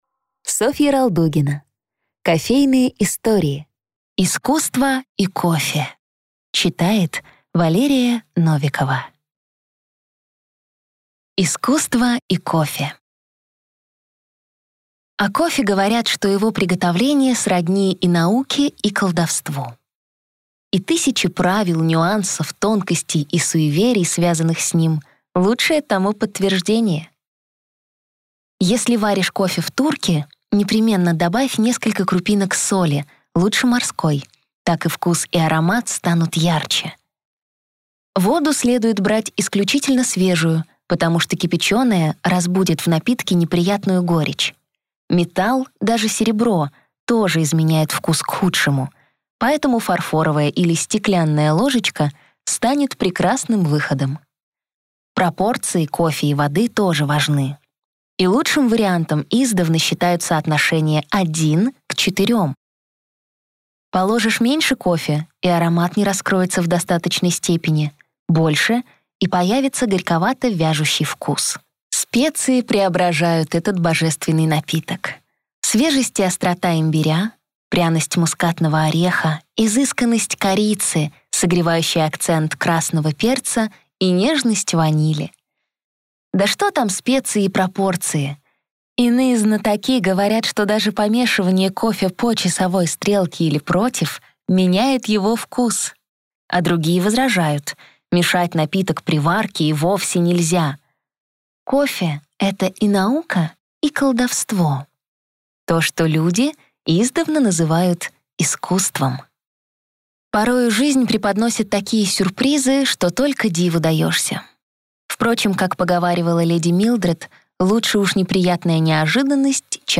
Аудиокнига Искусство и кофе | Библиотека аудиокниг